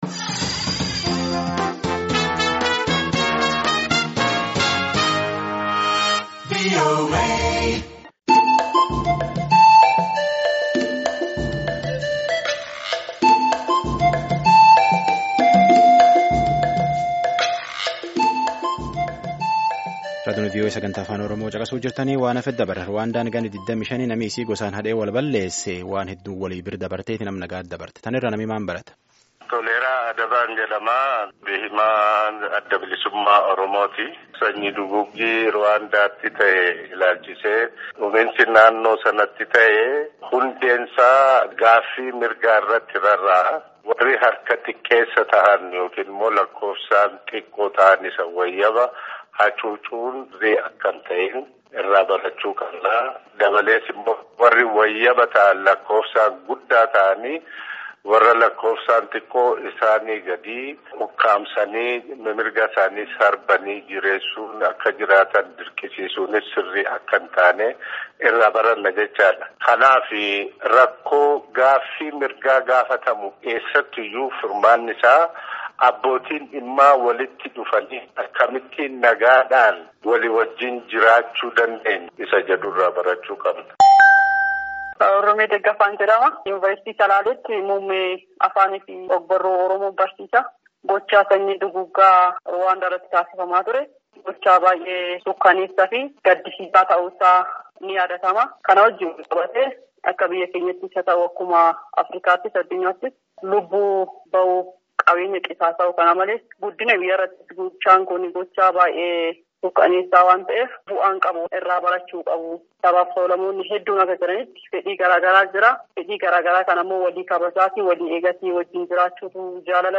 Namoonni adda addaa yadaa isaanii Raadiyoo Sagaalee Ameerikaaf kennanii jiru.